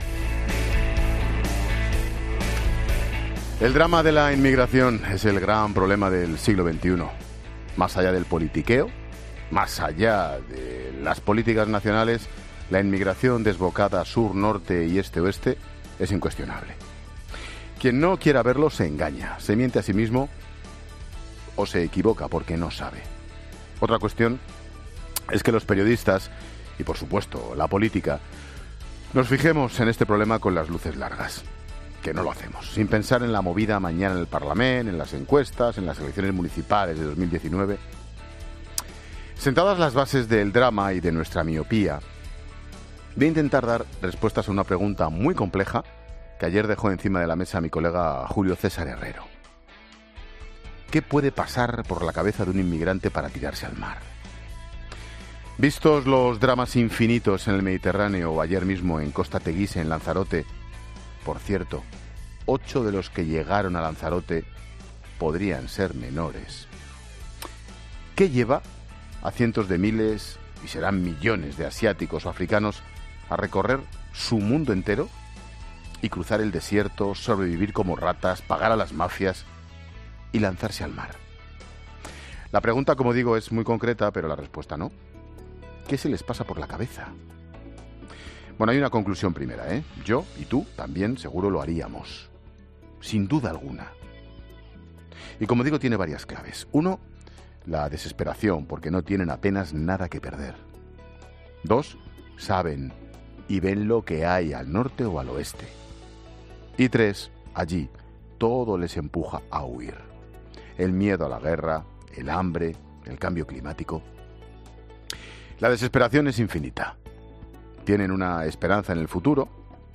Monólogo de Expósito
El comentario de Ángel Expósito sobre inmigración.